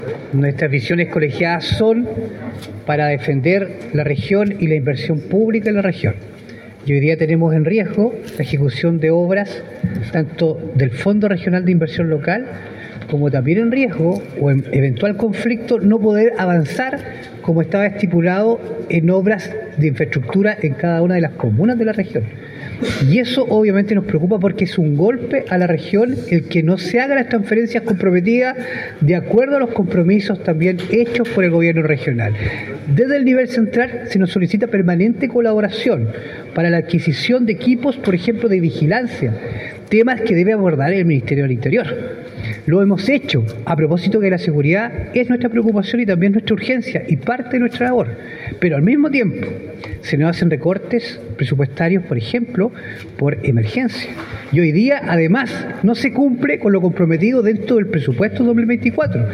El Consejero Regional de la Provincia de Osorno, Francisco Reyes, también se pronunció sobre el tema, resaltando que esta situación evidencia el problema de la centralización de los recursos en Chile, un tema que ha sido motivo de preocupación y discusión a nivel regional durante años.